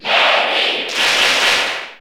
Category: Bowser Jr. (SSBU) Category: Crowd cheers (SSBU) You cannot overwrite this file.
Larry_Cheer_Italian_SSB4_SSBU.ogg